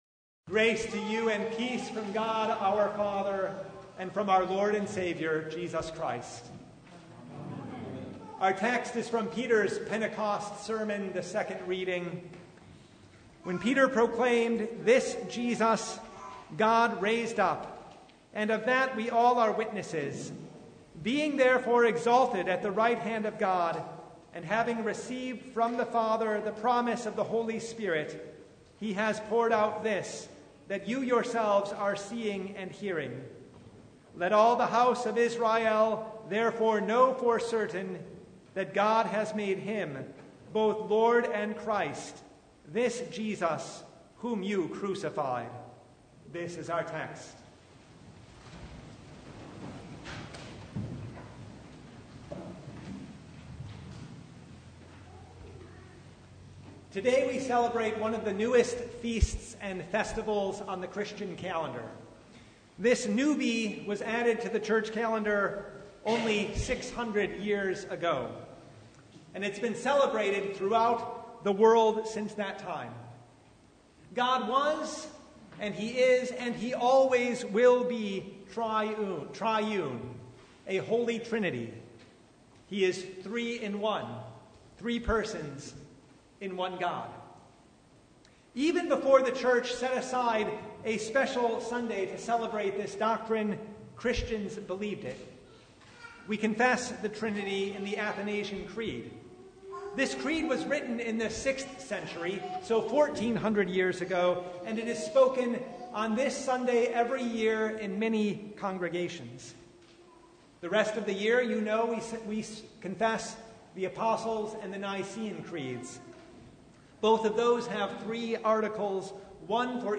Sermon Only « The Feast of the Holy Trinity